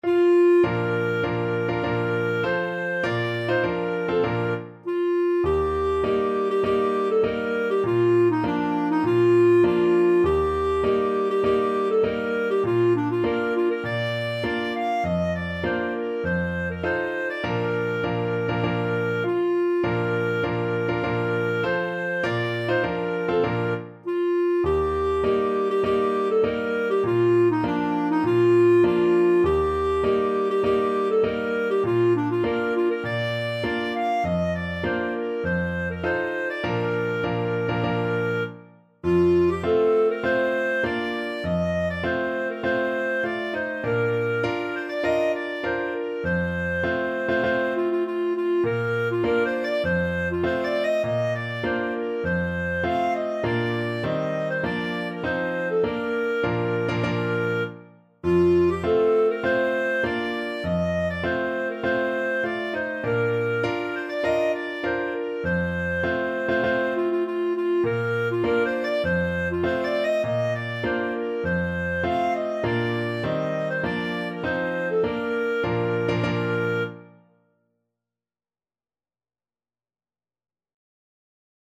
Clarinet
4/4 (View more 4/4 Music)
Bb major (Sounding Pitch) C major (Clarinet in Bb) (View more Bb major Music for Clarinet )
hohenfriedberger_march_CL.mp3